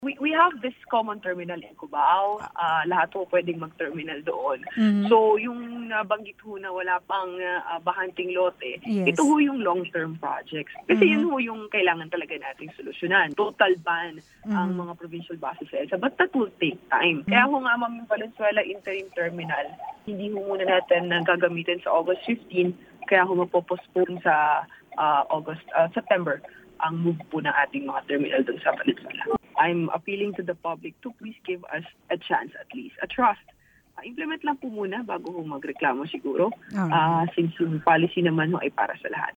Sa panayam ng programang Serbisyo ng Agila